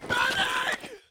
stunned.wav